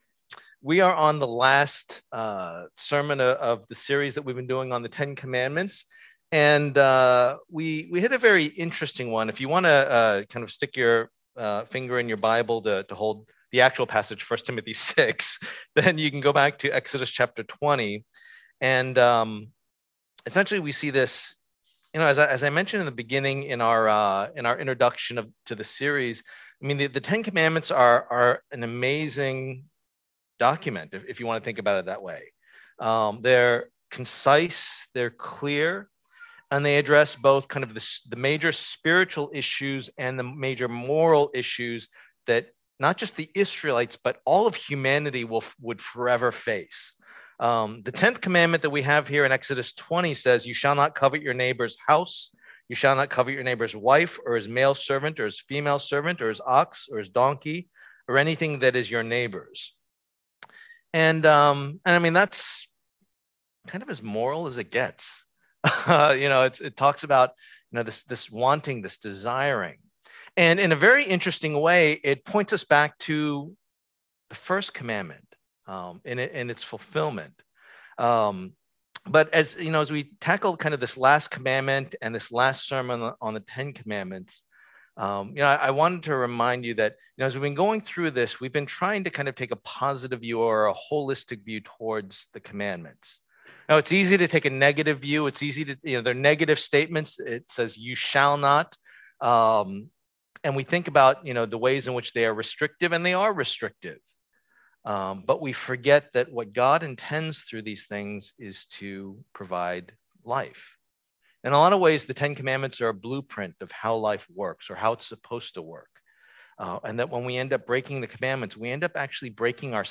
Passage: 1 Timothy 6:3-10 Service Type: Lord's Day